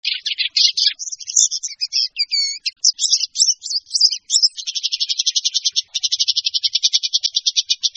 En cliquant ici vous entendrez le chant de la Pie grièche écorcheur.